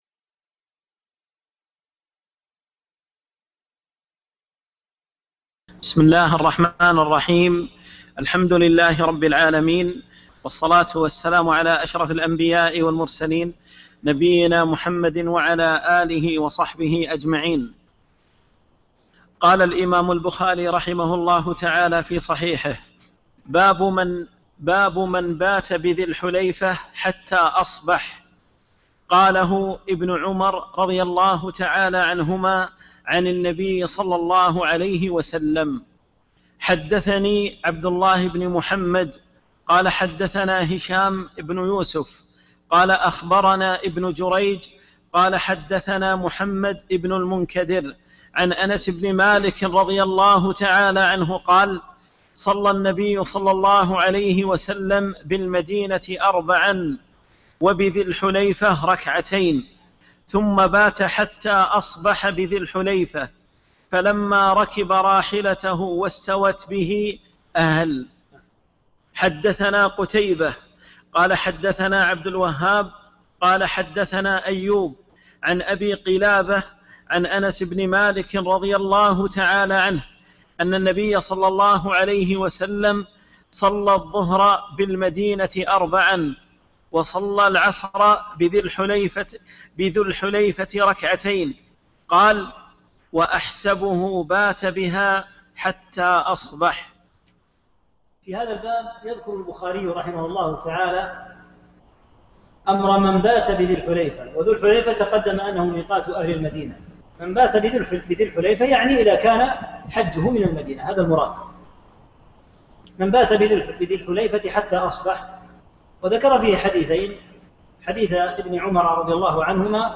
2 - الدرس الثاني